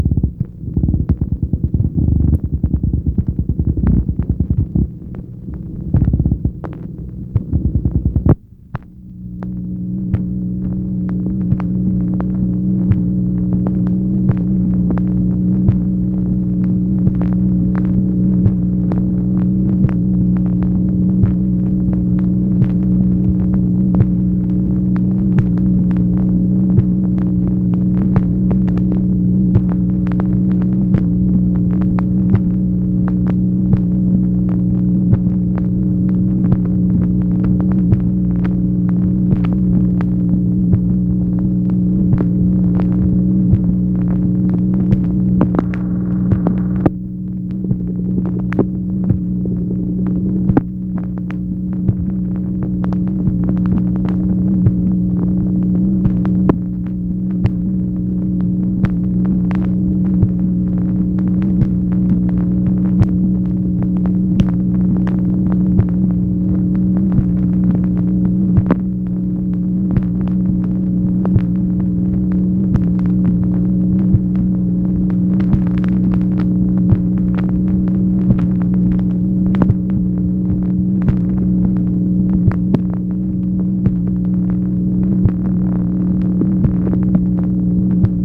MACHINE NOISE, January 13, 1964
Secret White House Tapes | Lyndon B. Johnson Presidency